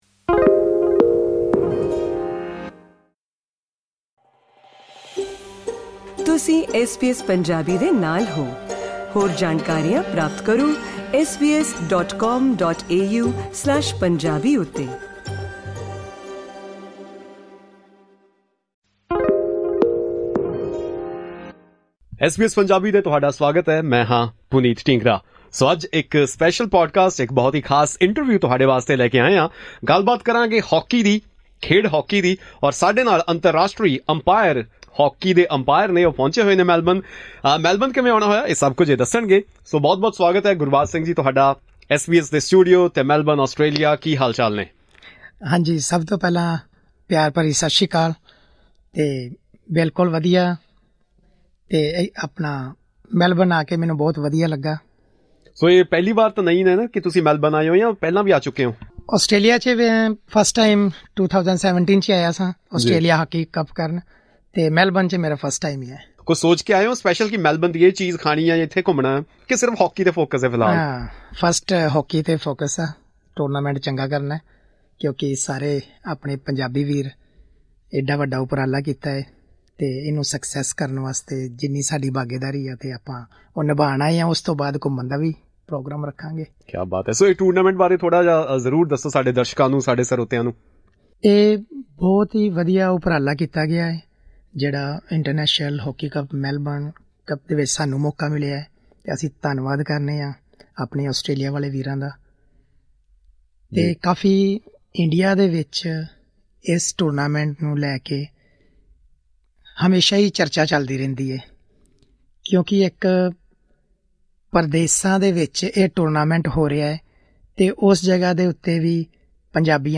Refusing to give up, he chose the path of coaching and umpiring and has now been contributing to the sport for over 12 years. To better support young athletes, he also pursued studies in Sports Science, aiming to help players avoid long-term injuries and receive the right guidance. Tune in to hear the full interview.